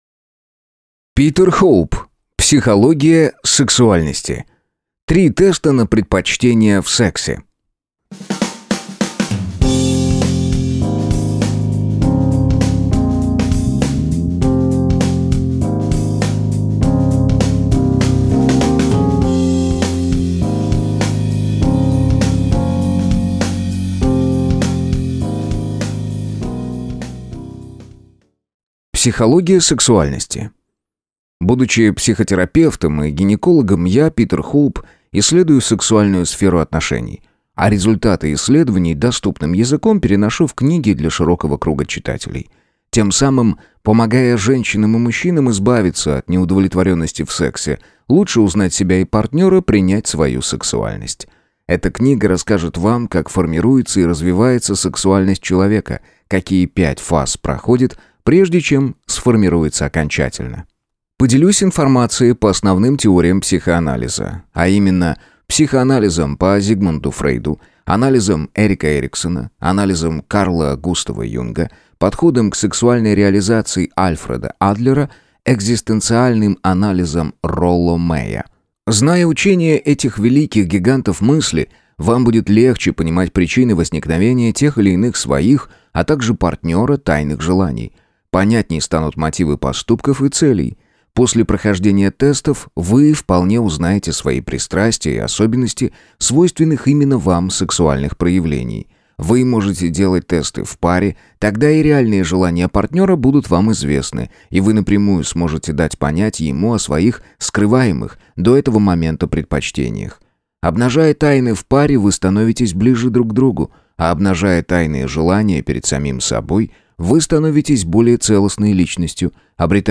Аудиокнига Психология сексуальности. 3 теста на предпочтения в сексе | Библиотека аудиокниг